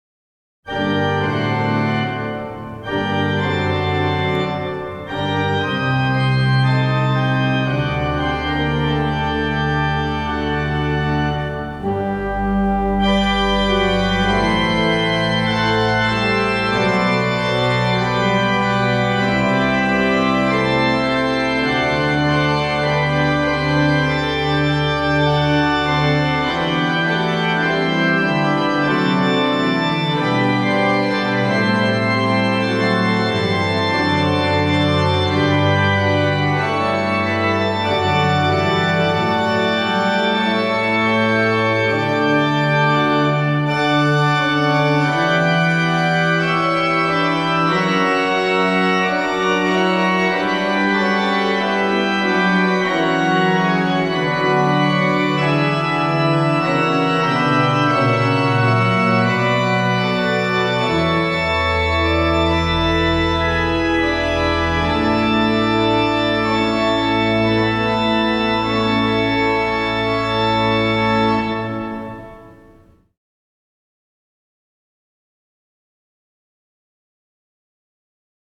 “Truly Great Processional” • (Pipe Organ)
I stumbled upon this live recording of a PROCESSIONAL I played on the pipe organ in 2002.
So it was necessary to play the entire piece from beginning to end.